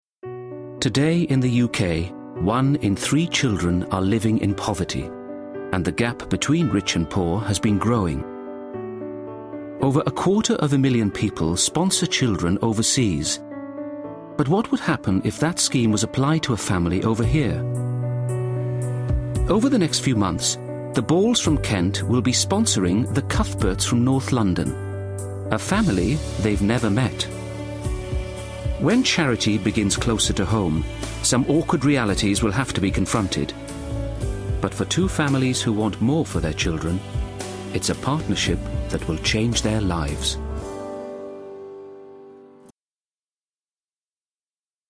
Commercial - Other Half - Soft, Calm, Informative